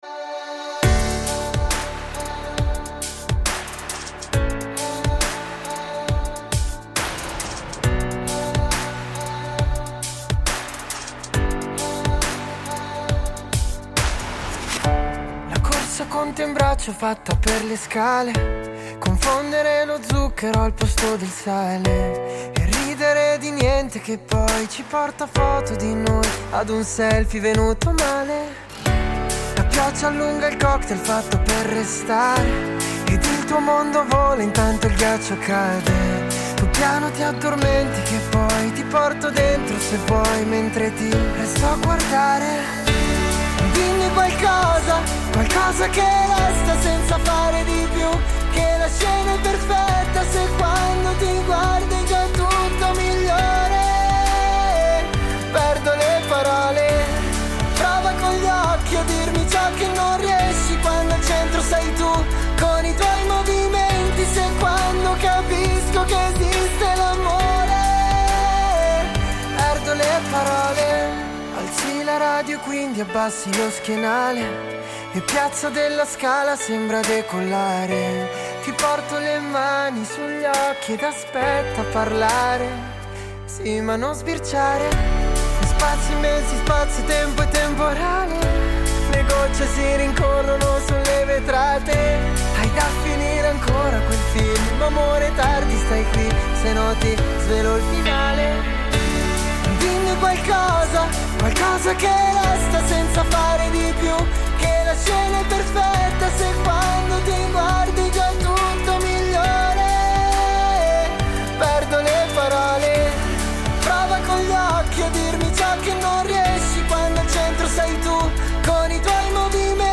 Incontro con Riccardo Marcuzzo, in arte RIKI